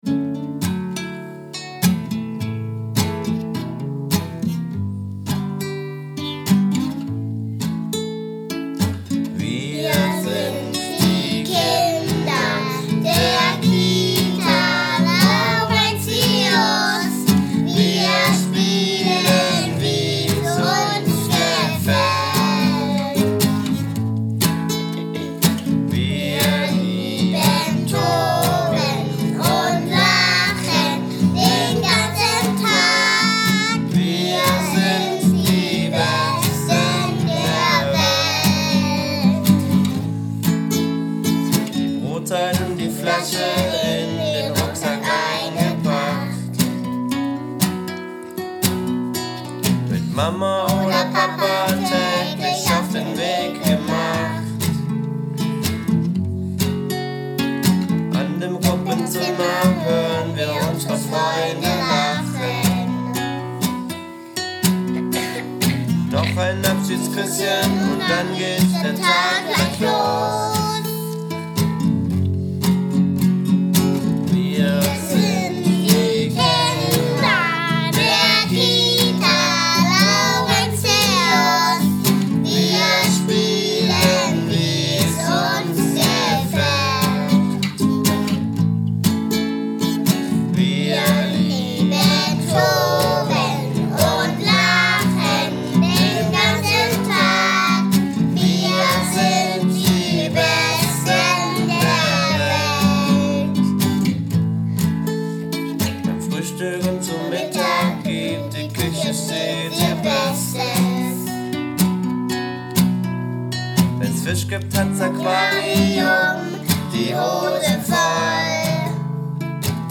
einige Kinder haben fleißig
final in einem Tonstudio gesungen